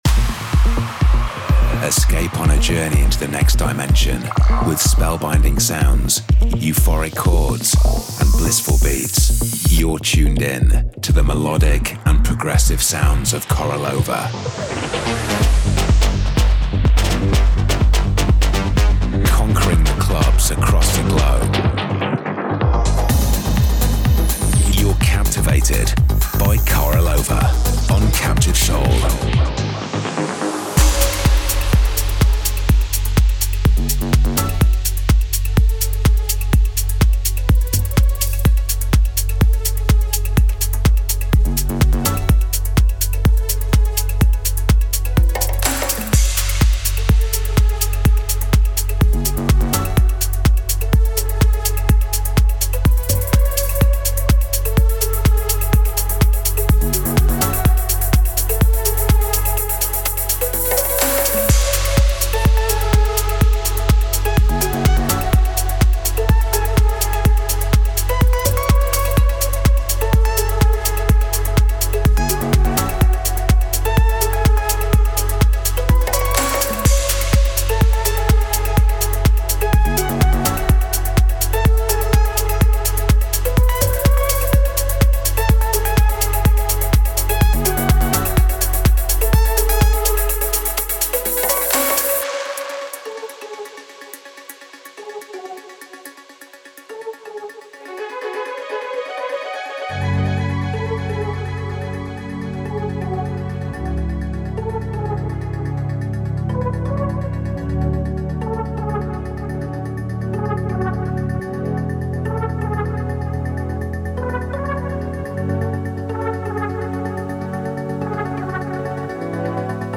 The bi-weekly radio show